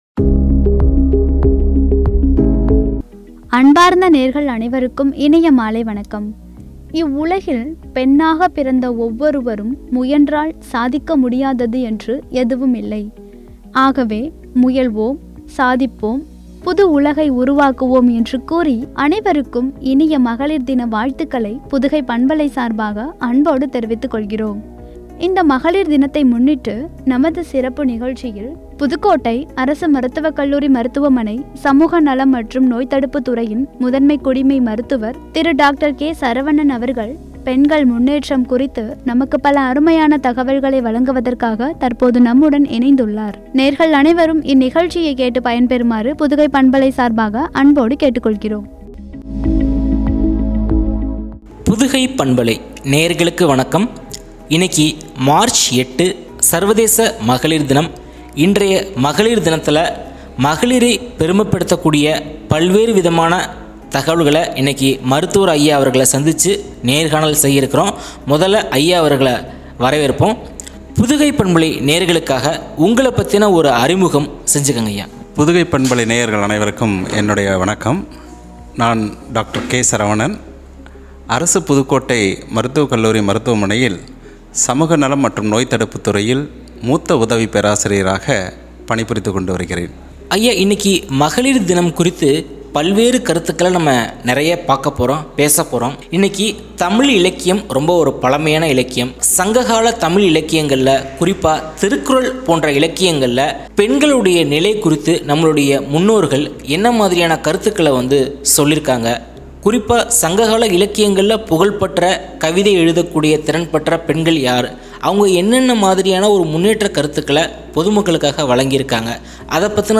“பெண்மையை போற்றுவோம்!” என்ற தலைப்பில் வழங்கிய உரையாடல்.